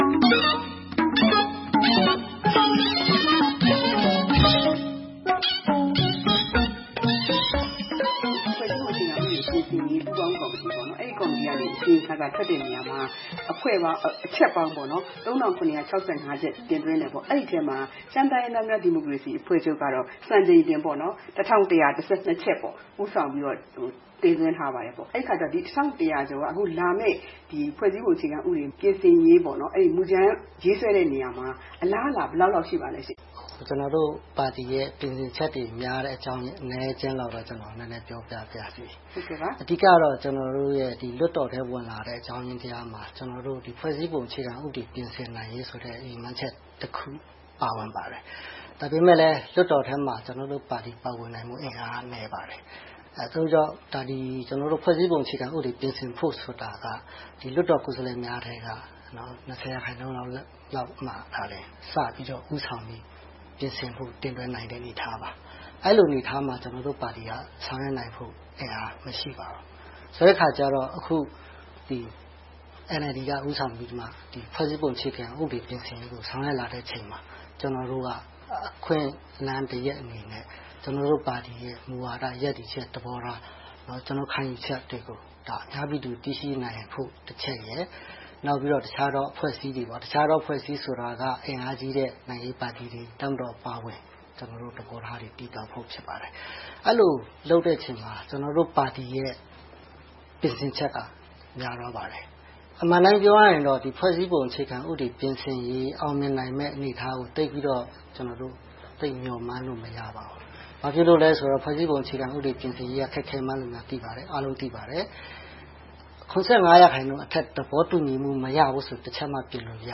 သြဂုတ် ၂၅၊ ၂၀၁၉ - ရှမ်းတိုင်းရင်းသားများ ဒီမိုကရေစီအဖွဲ့ချုပ်၊ မိုင်းရယ်ပြည်သူ့လွှတ်တော်ကိုယ်စားလှယ် စိုင်းသီဟကျော်က ပြောပါတယ်။
သီးသန့်တွေ့ဆုံ မေးမြန်းထားပါတယ်။